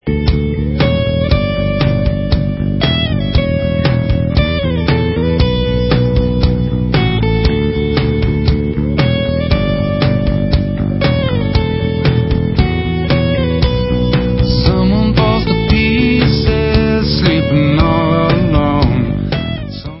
• styl: Alternative Rock, Hard Rock